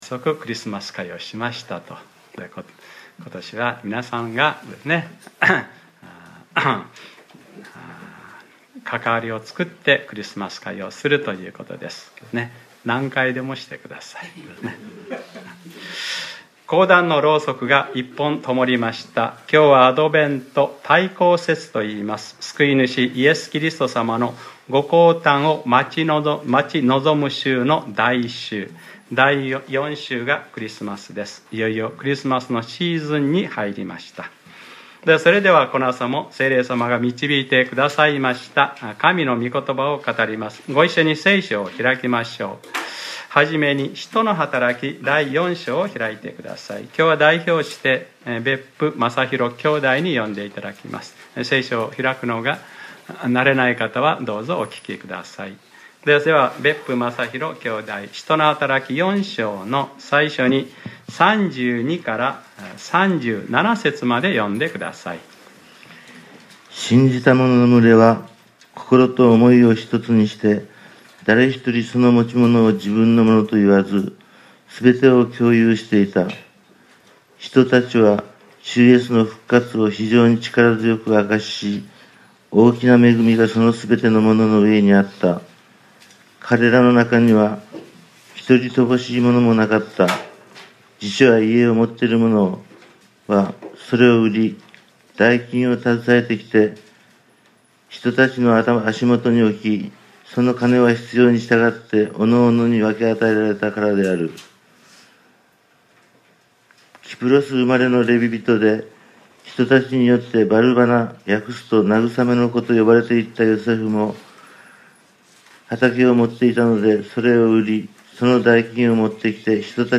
2017年11月26日（日）礼拝説教『使徒ｰ15：バルナバ（慰めの子）』